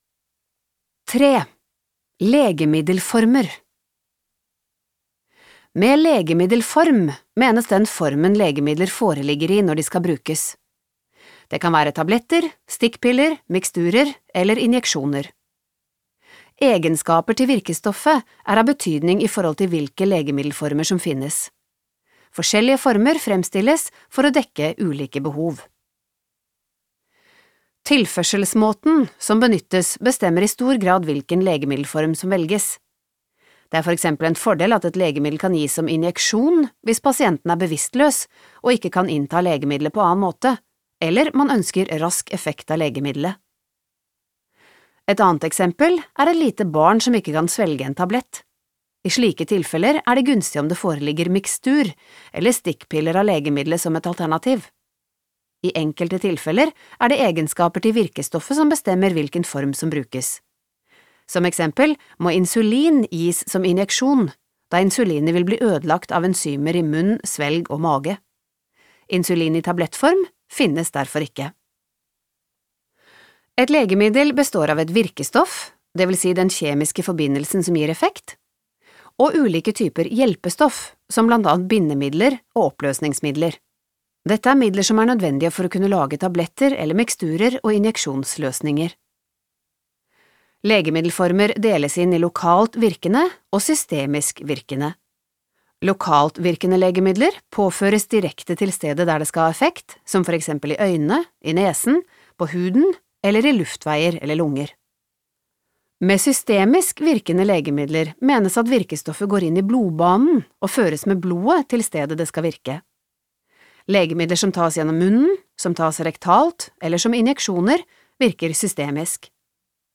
Det er også produsert en lydbok med samme innhold som e-boken.